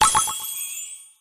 Appear__multi__Win_Sound.mp3